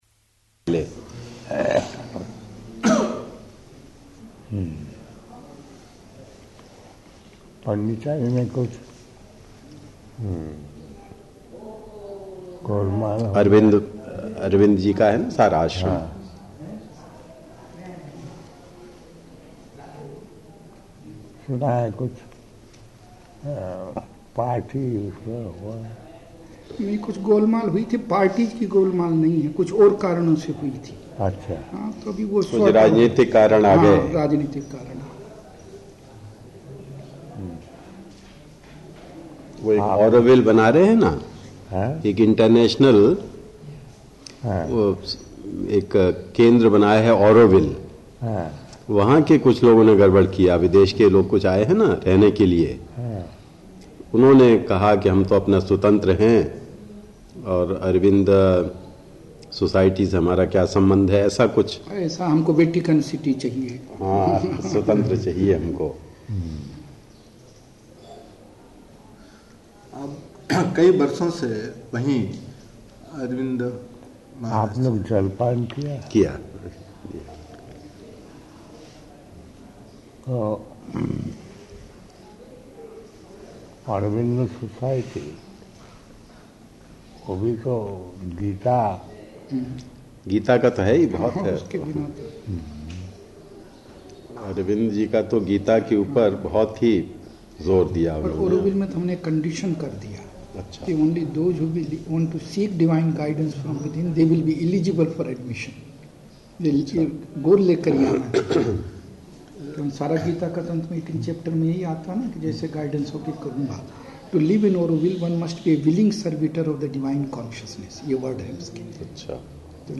-- Type: Conversation Dated